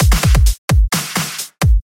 摇滚Dubstep鼓
描述：来自岩石的Dubstep鼓
Tag: 130 bpm Dubstep Loops Drum Loops 319.86 KB wav Key : Unknown